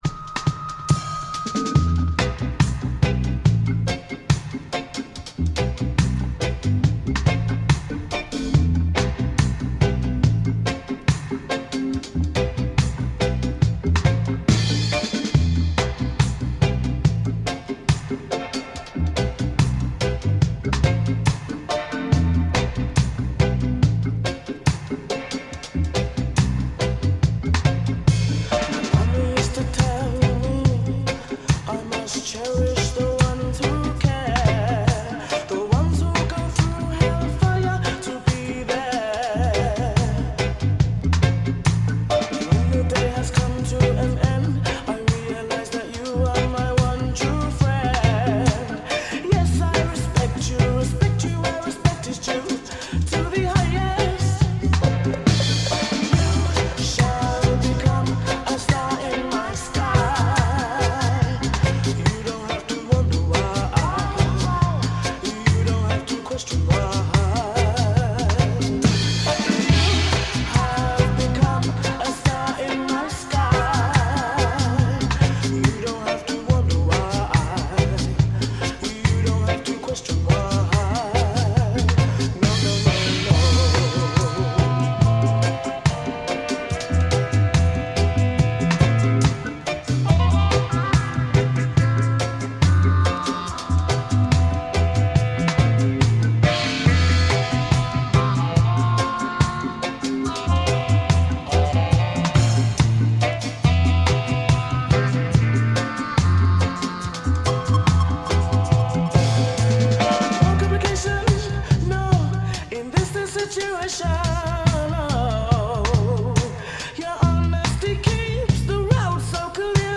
Nine-piece Brighton and London based band
have firmly established themselves on the dub reggae scene